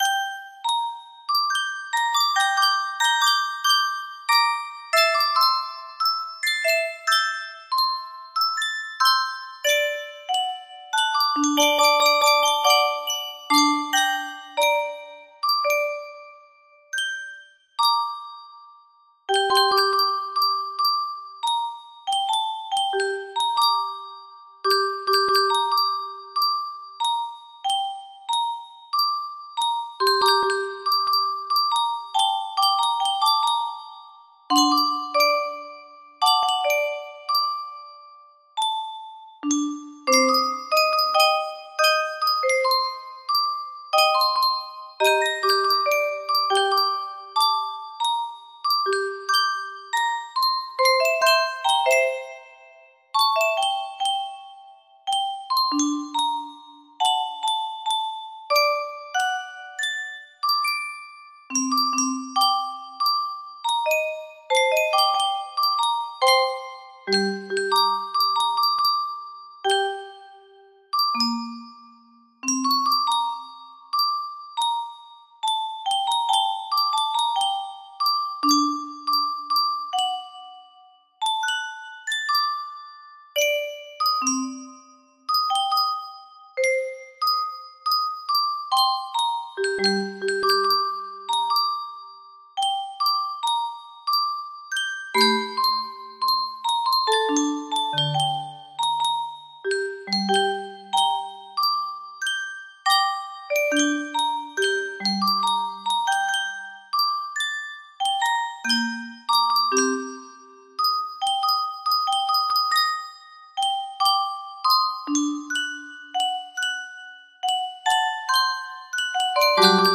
Whispers of Eternity music box melody
Full range 60